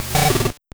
Cri de Lanturn dans Pokémon Or et Argent.